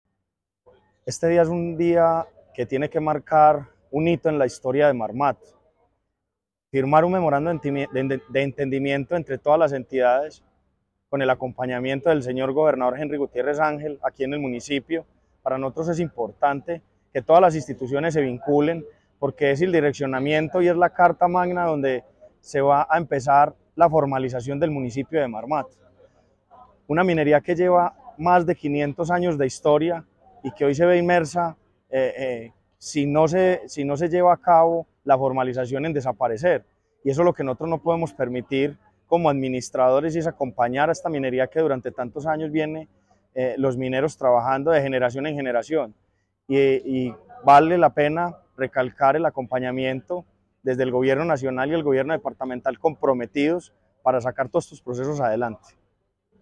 Carlos Alberto Cortés Chavarriaga – Alcalde de Marmato.
alcalde-de-Marmato-Carlos-Alberto-Cortes-Chavarriaga.mp3